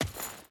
Footsteps / Dirt / Dirt Chain Run 2.ogg
Dirt Chain Run 2.ogg